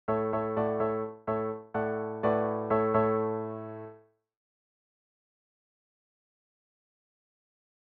To give you an idea of the effect we’re talking about, here are some simple short melodic fragments, first given in a major key, and then in a minor key equivalent:
A  D/A A  D/A  Bm/A  A (
These are just midi files that I’ve posted here, so you’ll have to use your imagination and your own instrumentation to bring them to life.